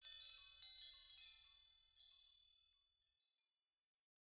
shimmer.ogg